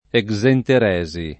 exenteresi [ e g@ enter $@ i ]